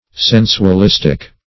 Search Result for " sensualistic" : The Collaborative International Dictionary of English v.0.48: Sensualistic \Sen`su*al*is"tic\, a. 1.